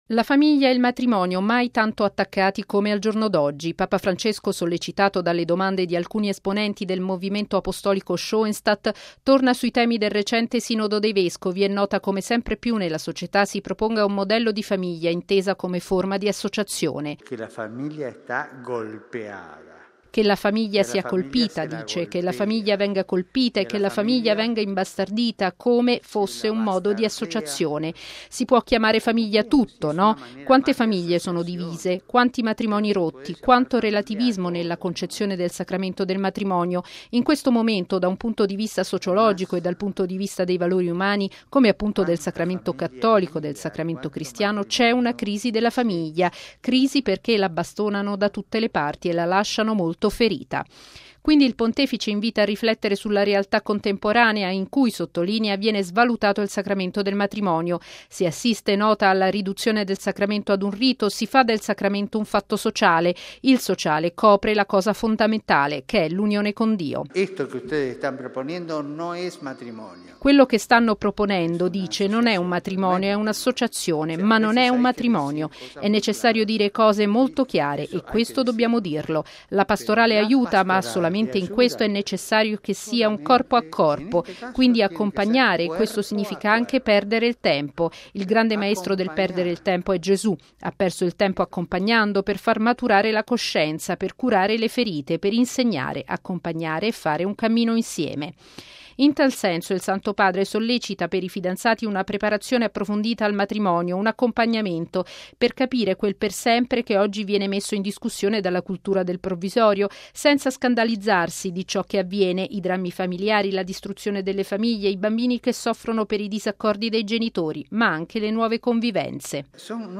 L’incontro, a cui hanno partecipato circa 7.500 persone, è stato animato da un dialogo dei presenti col Pontefice e da testimonianze e video di comunità, sposi, famiglie e giovani provenienti da una cinquantina di Paesi.